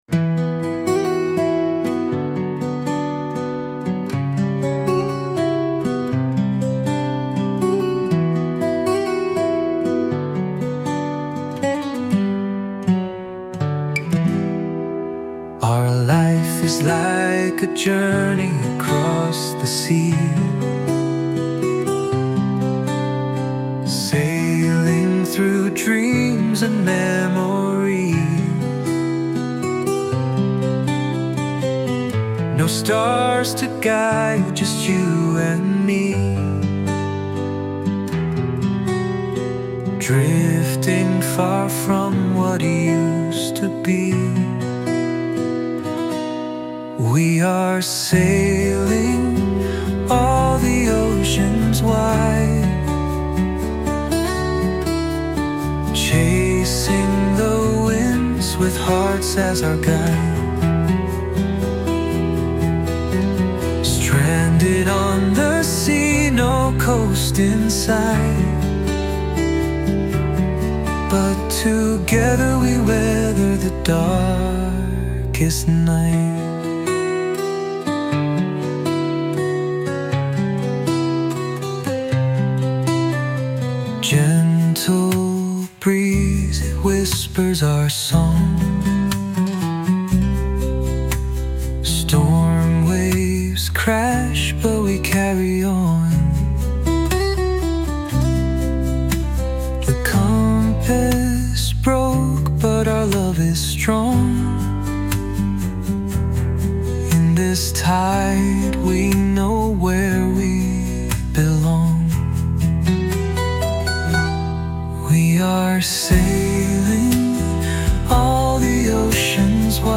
Genre:                  Rock/Pop